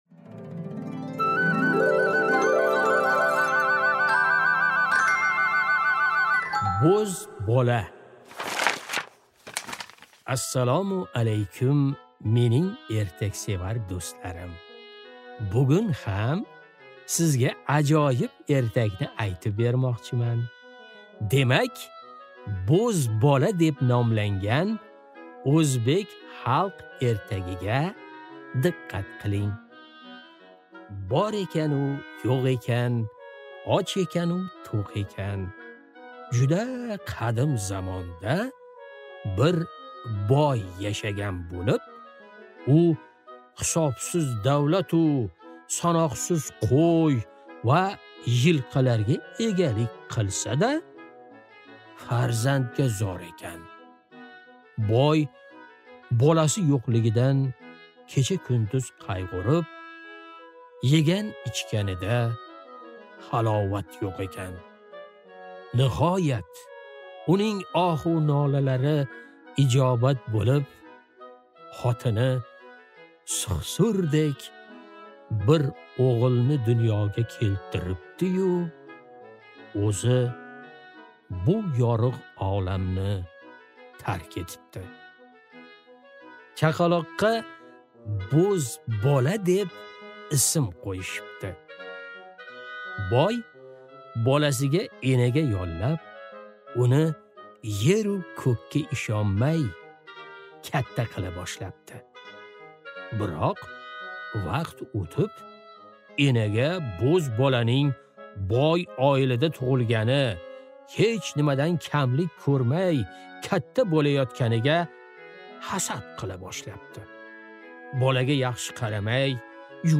Аудиокнига Bo'z bola | Библиотека аудиокниг